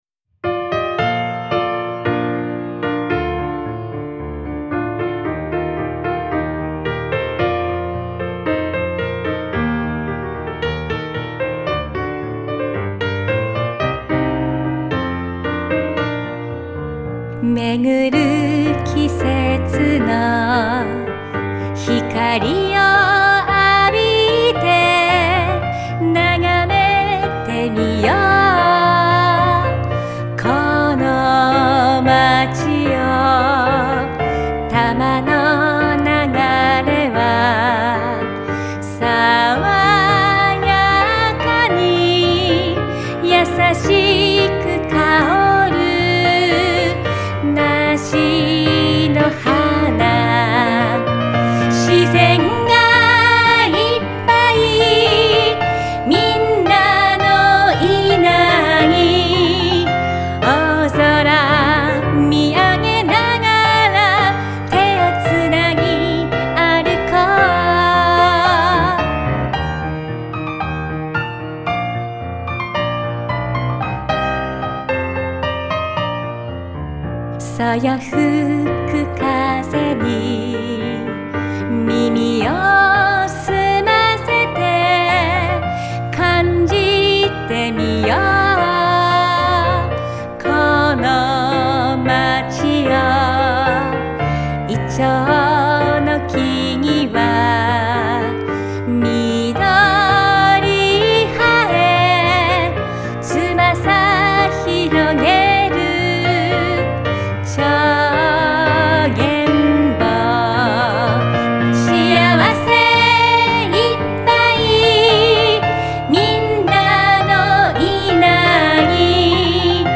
ソロピアノアレンジ